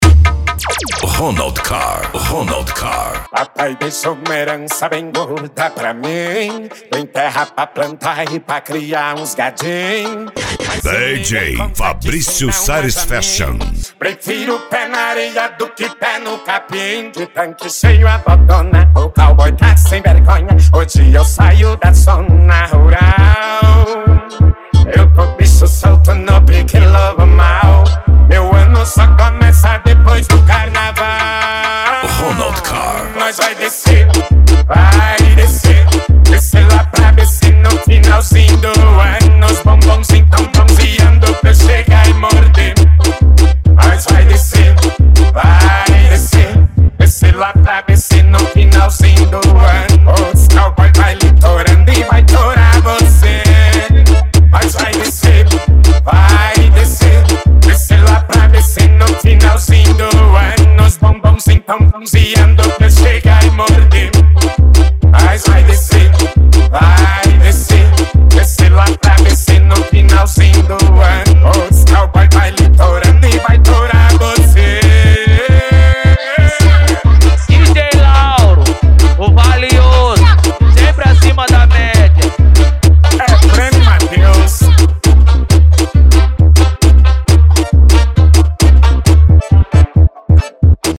Funk
Musica Electronica